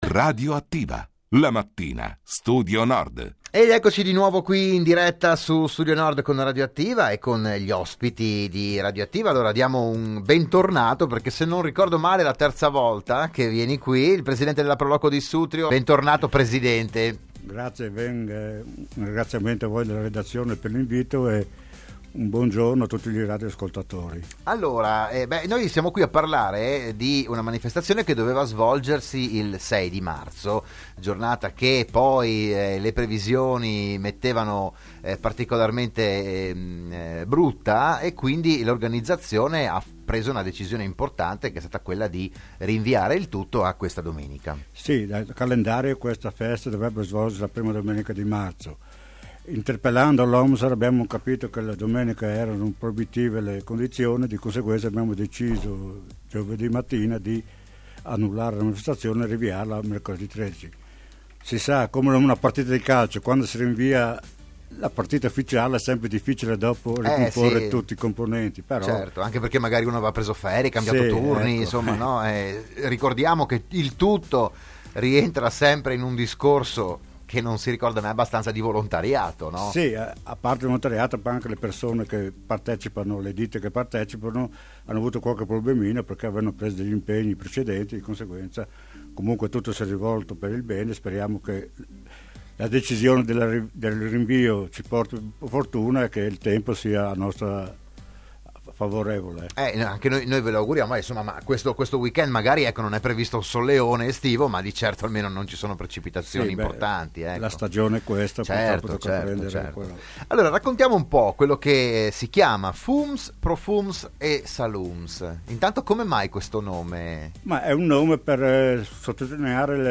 Domenica 13 marzo il suggestivo viaggio gastronomico con protagonista il salame. Il podcast dell'intervista